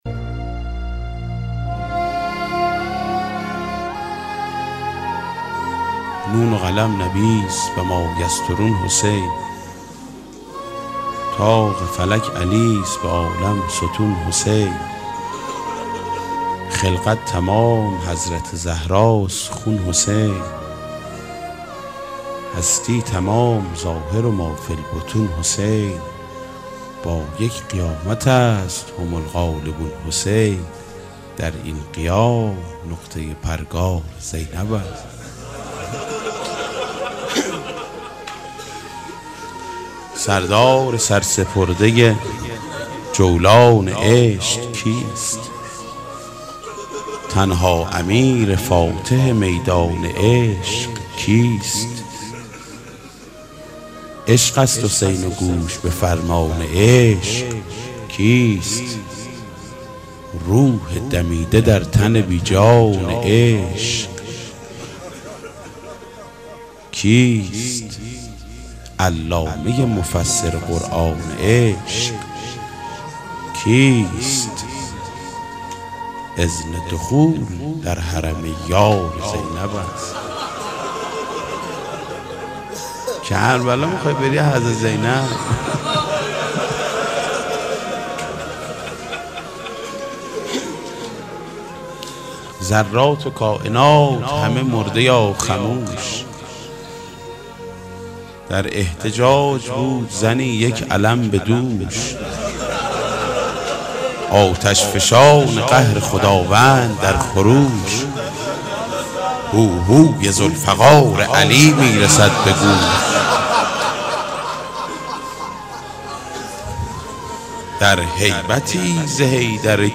در این قیام نقطه پرگار زینب است/شعر خوانی حاج محمود کریمی
در شب شام غریبان حضرت سیدالشهدا (ع) عقیق شنیدن شعری از حاج محمود کریمی در رثای حضرت زینب کبری با صدای خودش را به شما پیشنهاد می دهد.